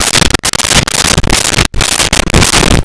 Pay Phone Receiver Toggle
Pay Phone Receiver Toggle.wav